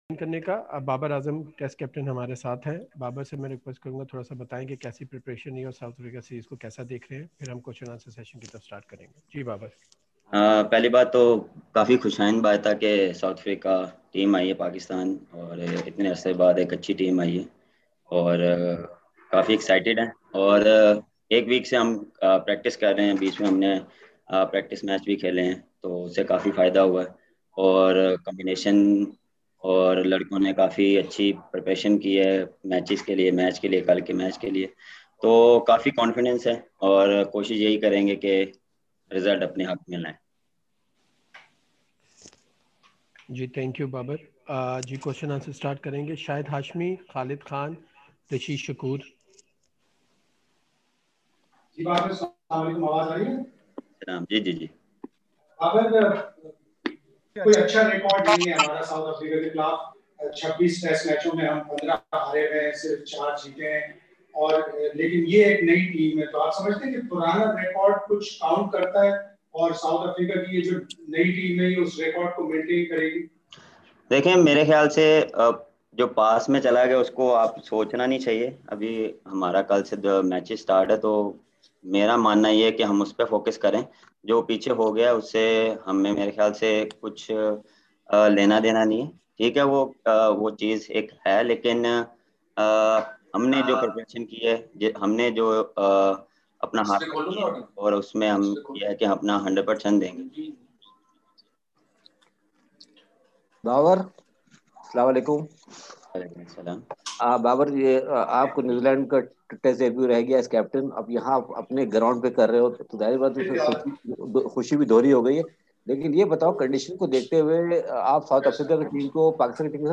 Babar Azam, the captain of Pakistan men’s Test team, interacted with media via videoconference call today, a day ahead of the first Test match against South Africa at the National Stadium Karachi.